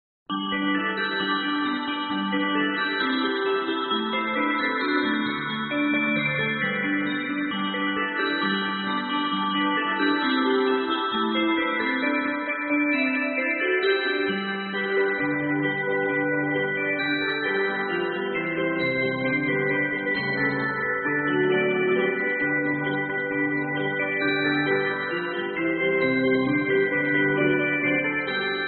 rt-bells.mp3